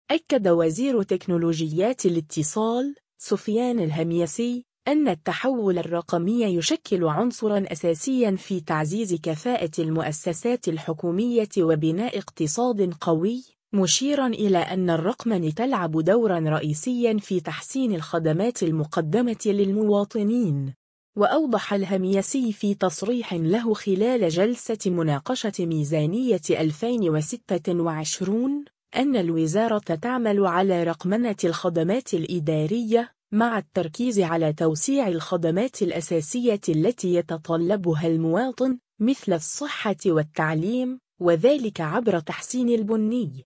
وأوضح الهميسي في تصريح له خلال جلسة مناقشة ميزانية 2026، أن الوزارة تعمل على رقمنة الخدمات الإدارية، مع التركيز على توسيع الخدمات الأساسية التي يتطلبها المواطن، مثل الصحة والتعليم، وذلك عبر تحسين البنية التحتية الرقمية في كافة أنحاء البلاد.